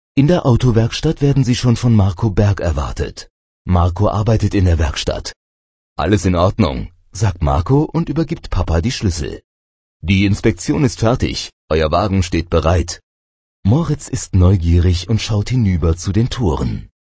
Hörbuch Seite 2